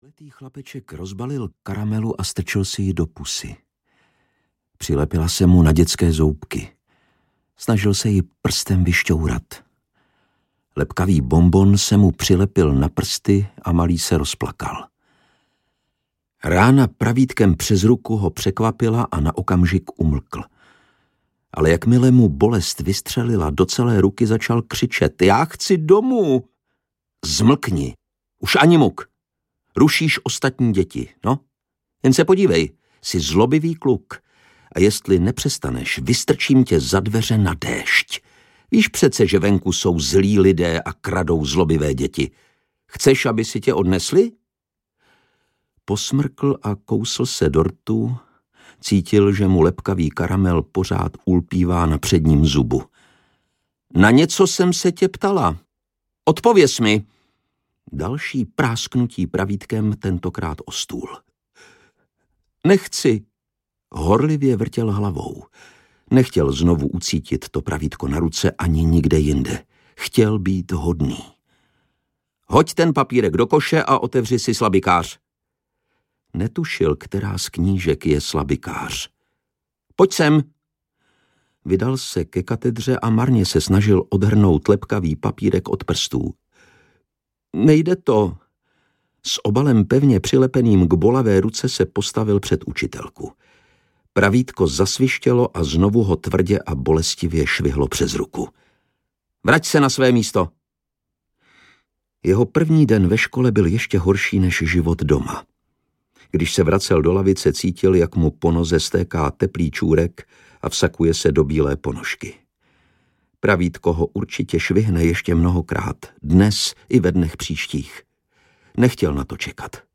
Ukázka z knihy
• InterpretLukáš Hlavica